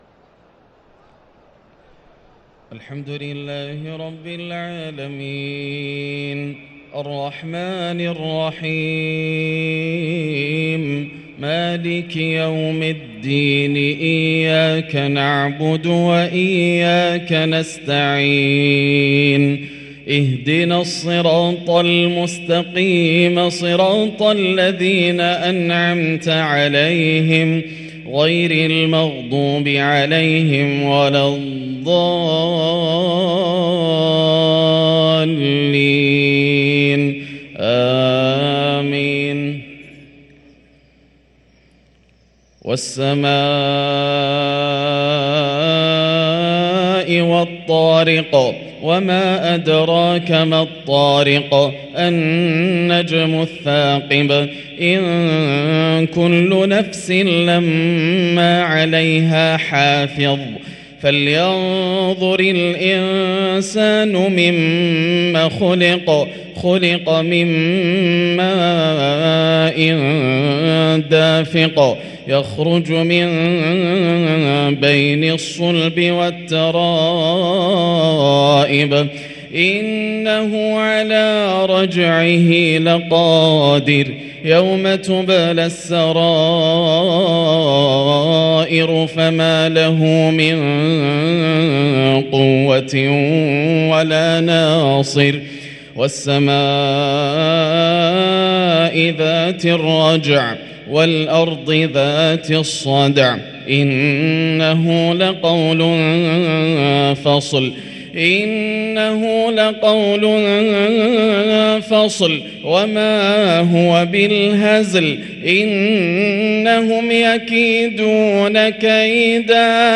صلاة المغرب للقارئ ياسر الدوسري 22 رجب 1444 هـ